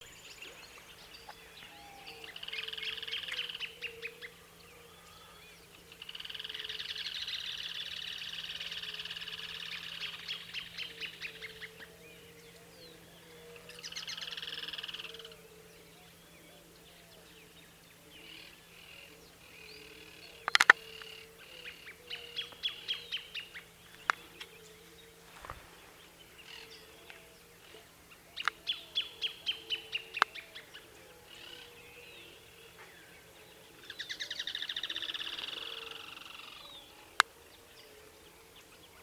Certhiaxis cinnamomeus
Yellow-chinned Spinetail
[ "alarm call" ]